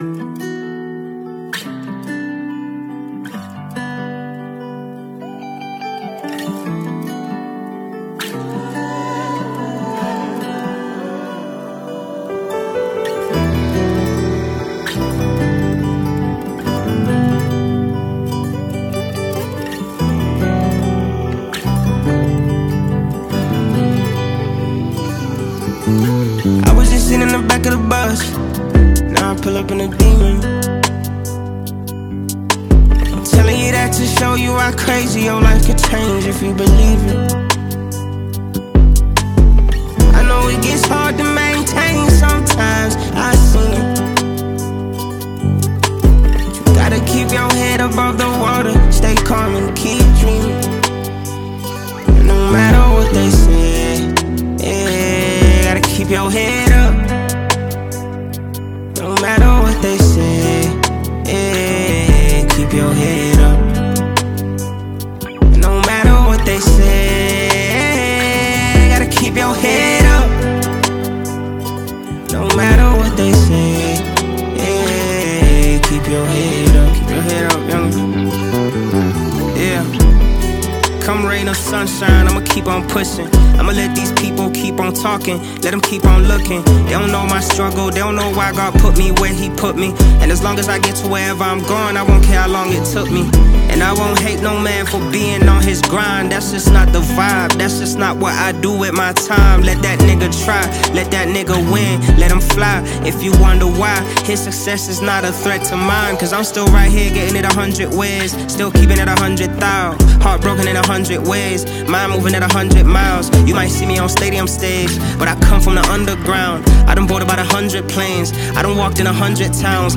powerful vocals add gravitas to the record